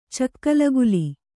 ♪ cakkalaguli